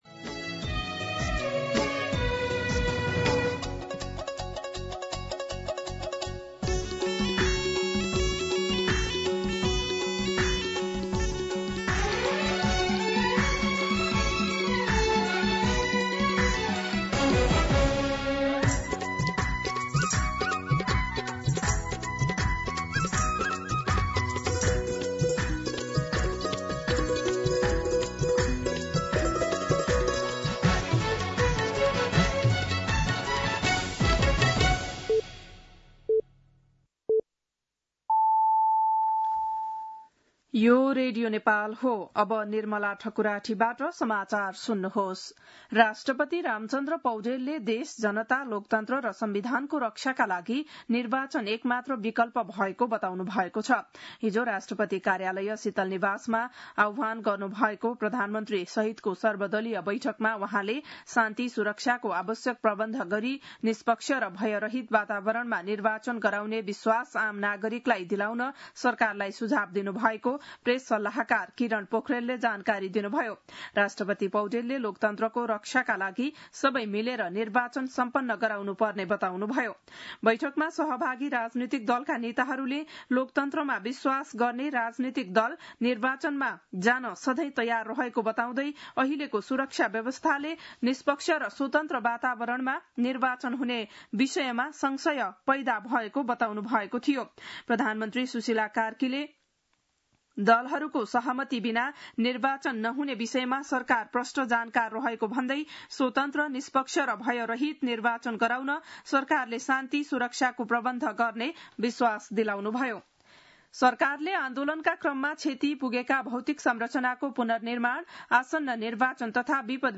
बिहान ११ बजेको नेपाली समाचार : २५ असोज , २०८२
11-am-Nepali-News-2.mp3